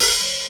• 90s Quiet Pop-Folk Open Hat Sample E Key 39.wav
Royality free open hi hat tuned to the E note. Loudest frequency: 6172Hz
90s-quiet-pop-folk-open-hat-sample-e-key-39-IsS.wav